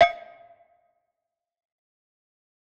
Destroy - Perc Magic.wav